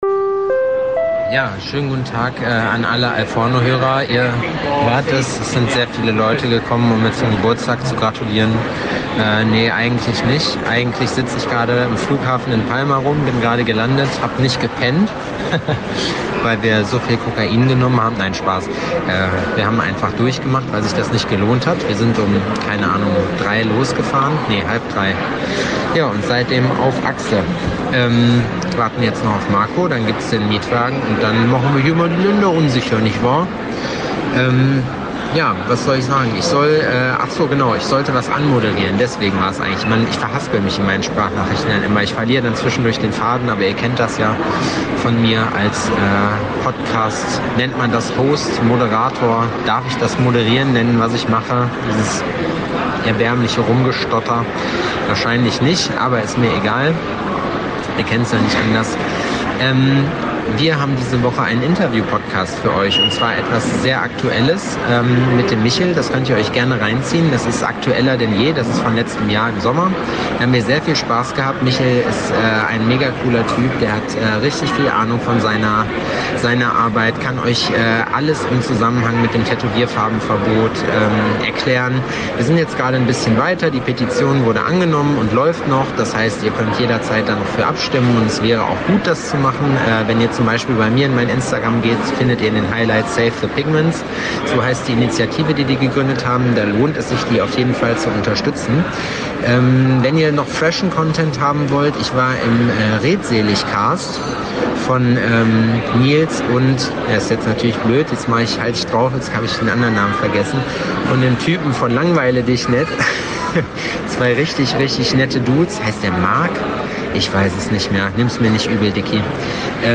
im Interview ~ AL FORNO Podcast